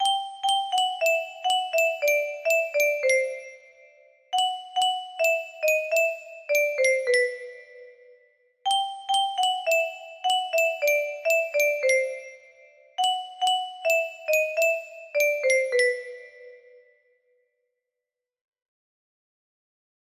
Alborea music box melody